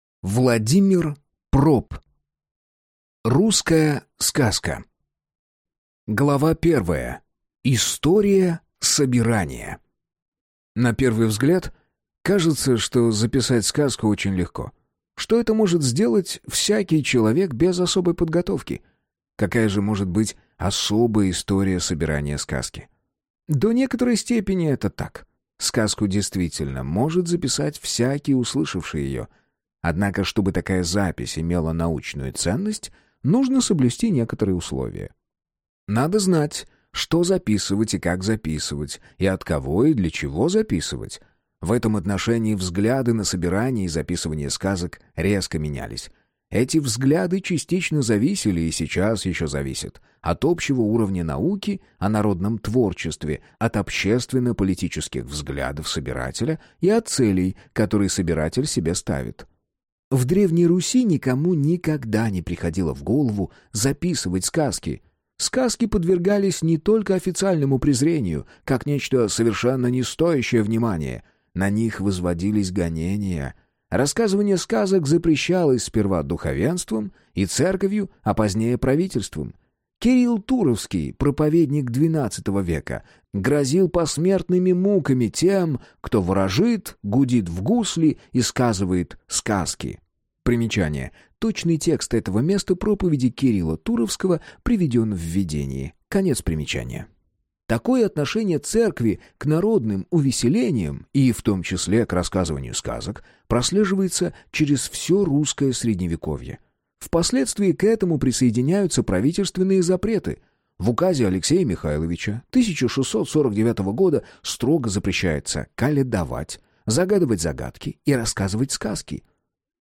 Аудиокнига Русская сказка | Библиотека аудиокниг